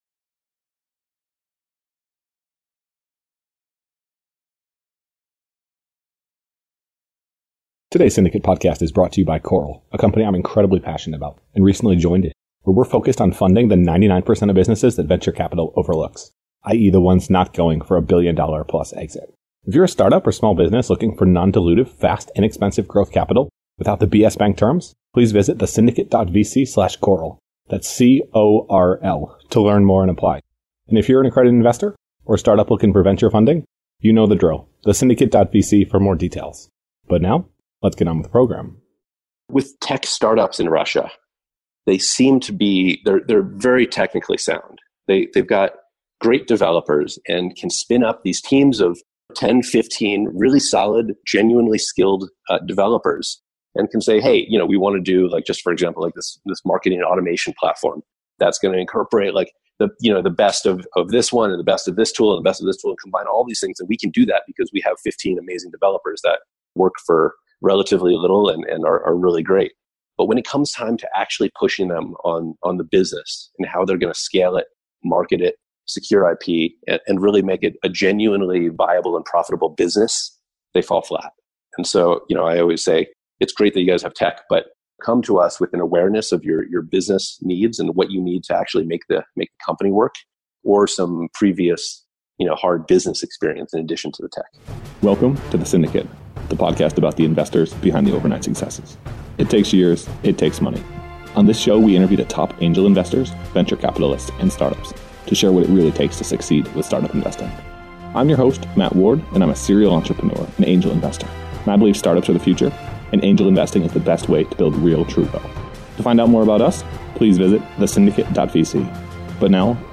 Discussing International Investing and Russian Startups on The Syndicate Podcast